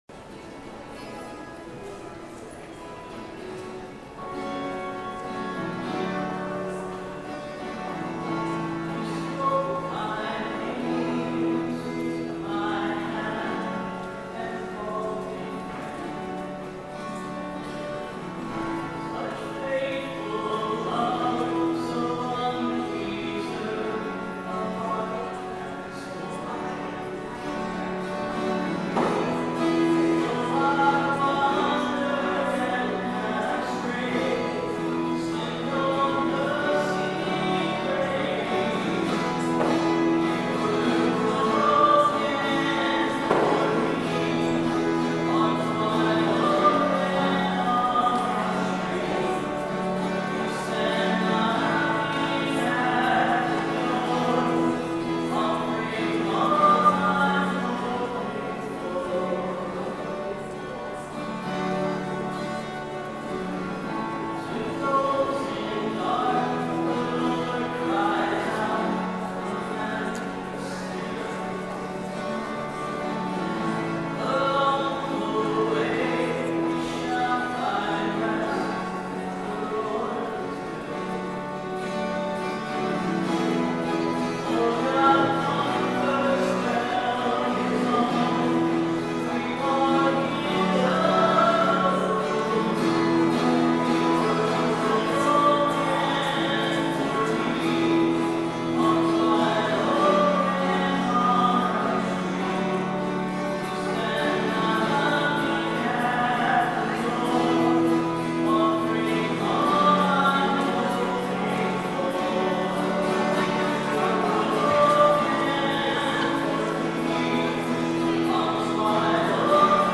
09/29/13 10:30 Mass Recording of Music
Today's mass, I amplified the sound equally across all songs. So this way you can hear how the sound is really balanced -- or not balanced -- whatever the case may be.
========================================== Music from the 10:30 Mass on the 26th Sunday in Ordinary Time, September 29, 2013: Note that all spoken parts of the Mass have been removed from this sequence, but ALL hymns and Mass parts are included (see above comment for 'why'). 092913 All Music.mp3